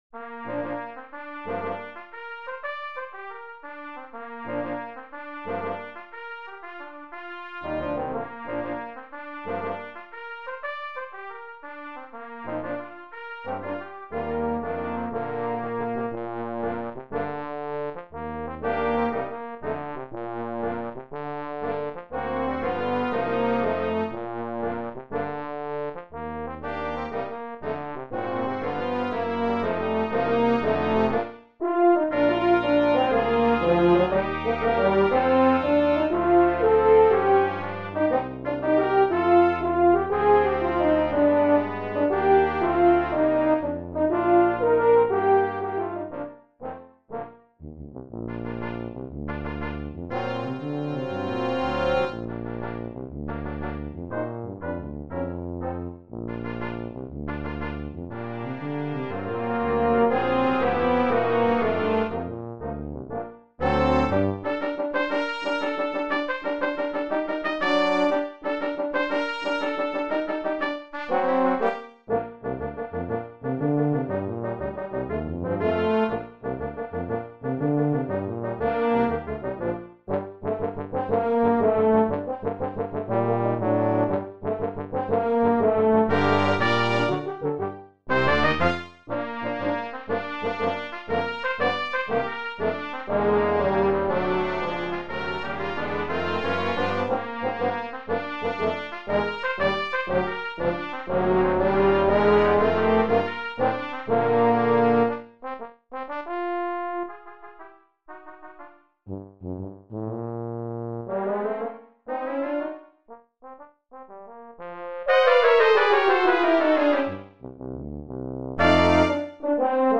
Brass Quintet
Listen to synthesized versions.